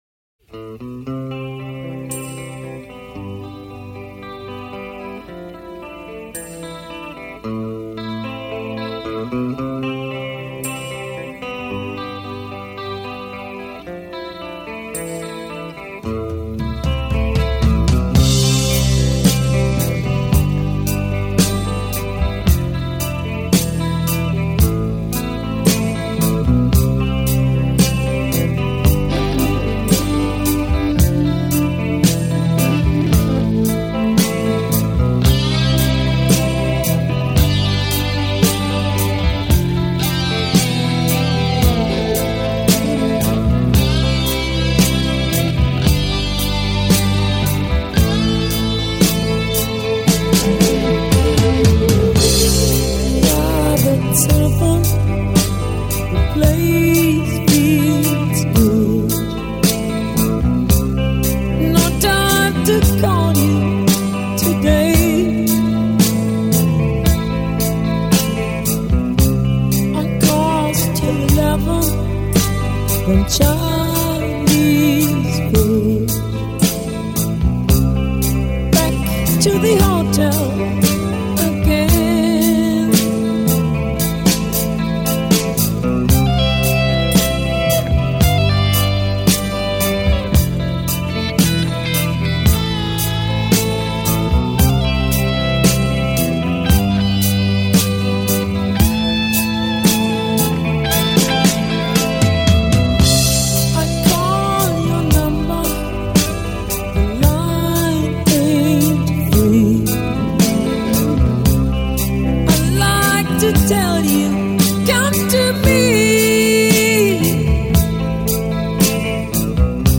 Жанр: Hard 'n' Heavy